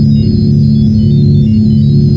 sciencebleep.wav